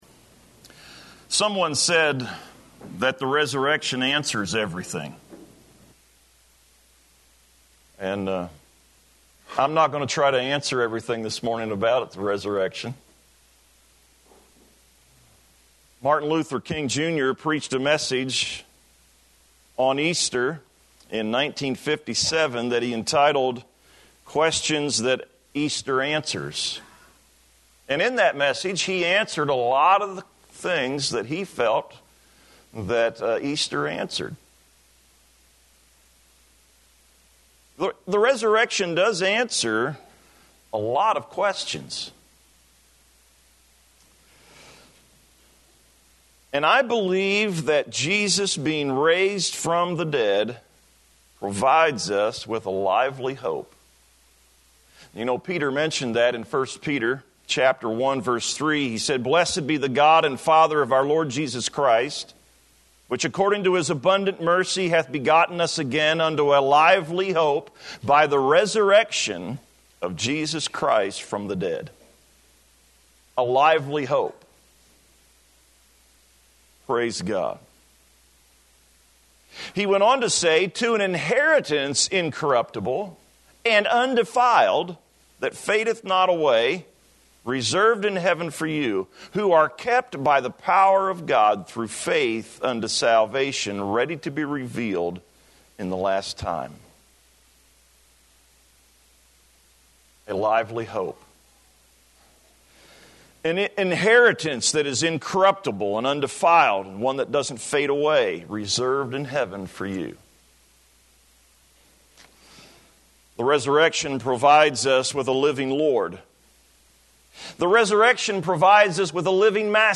2018-4-1-am-sermon.mp3